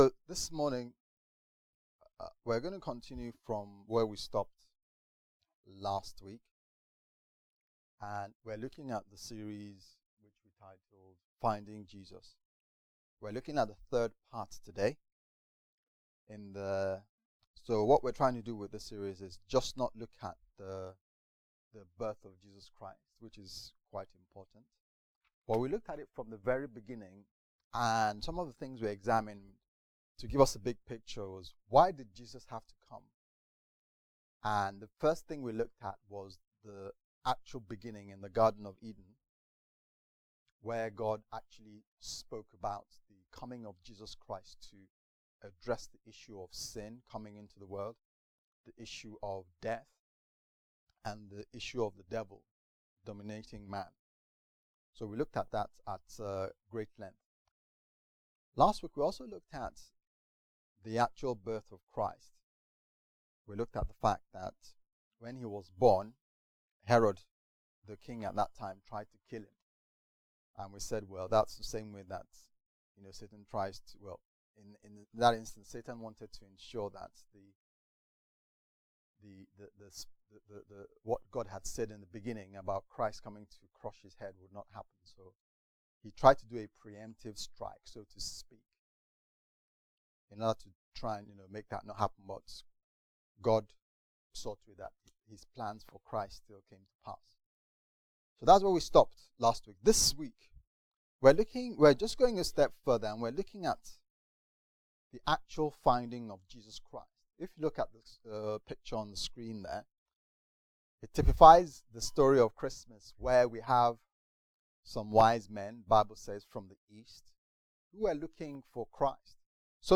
Finding Jesus Service Type: Sunday Service « Finding Jesus